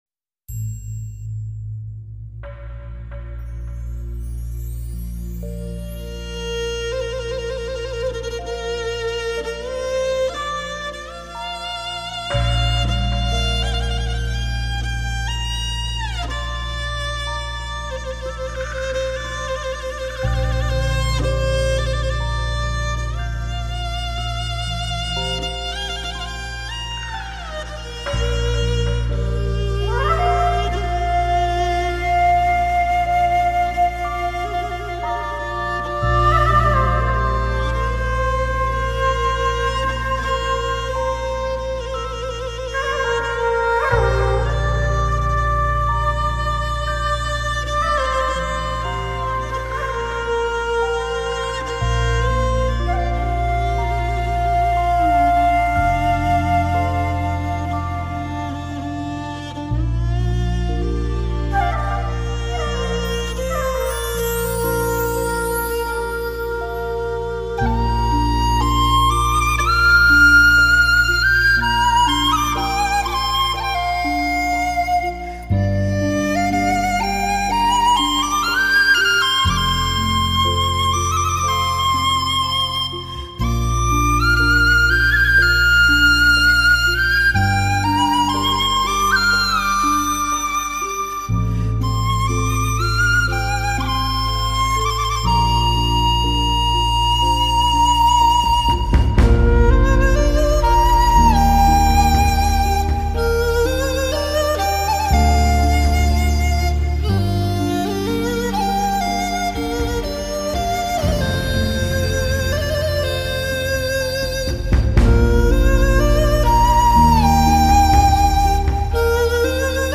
笛声散发出蓝宝石清冷的光芒，切割了声线陕隘的空间，回环起伏至天际之上……